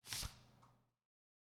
page turn.wav